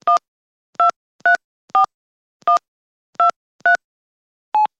Звуки набора телефона
В коллекции представлены как современные тональные сигналы мобильных устройств, так и характерный щелкающий звук старого дискового телефона.